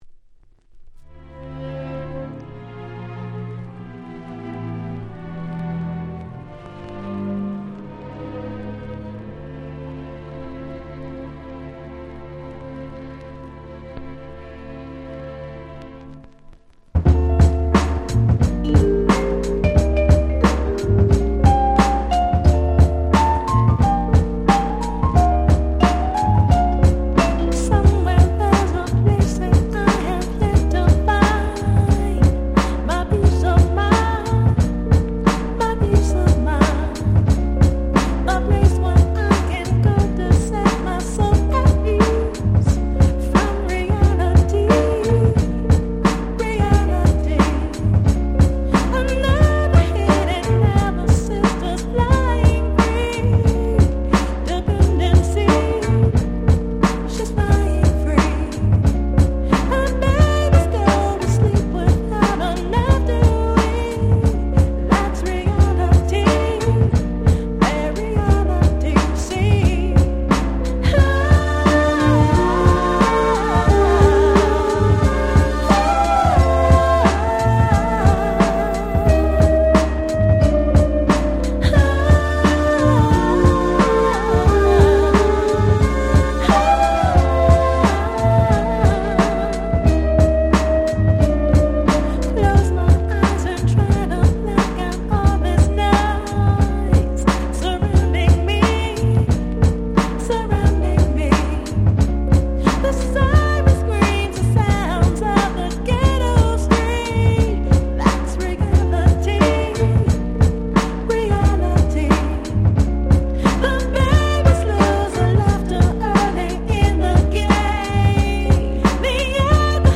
95' Super Nice R&B !!
Jazzyで艶っぽい大人のHip Hop Soulです！！